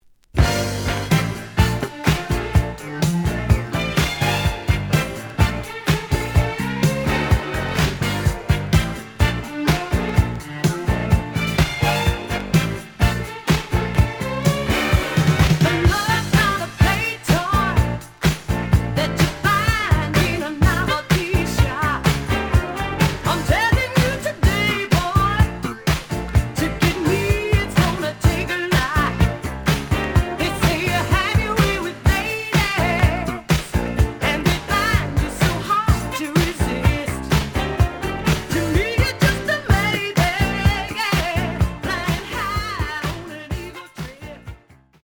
The audio sample is recorded from the actual item.
●Genre: Disco